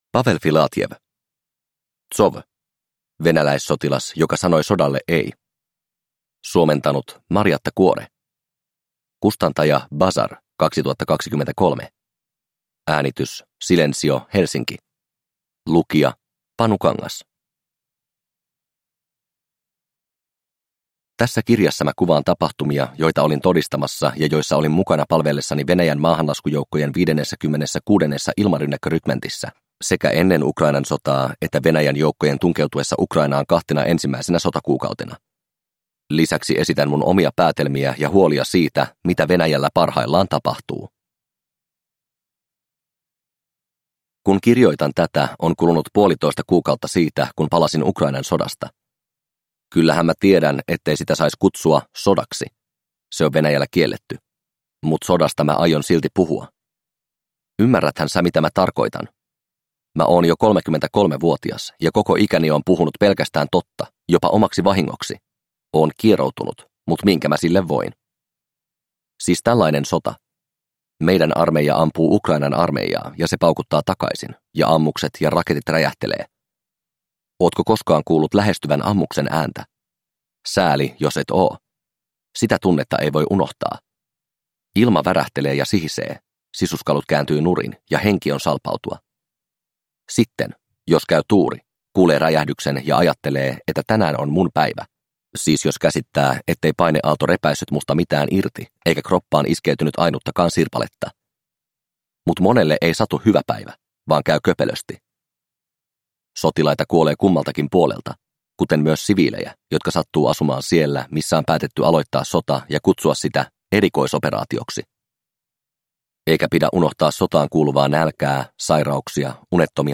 ZOV – Ljudbok